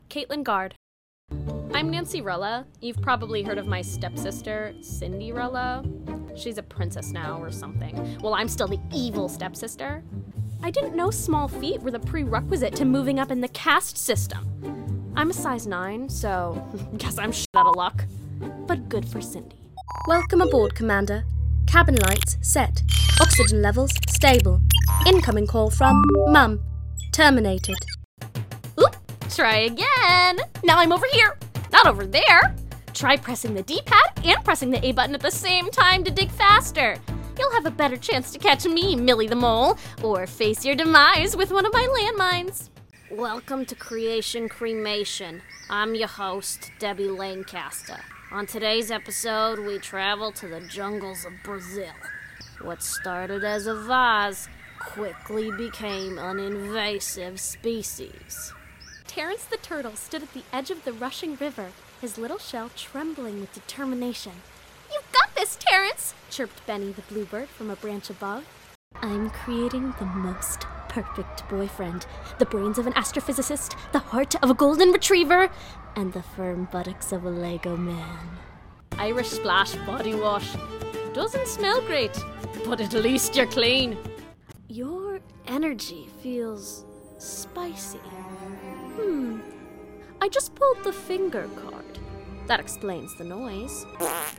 Sparkling, Expressive, Natural Warmth
Character Demo
American (northeast), Working Class British (Cockney), Irish, Posh British, Southern American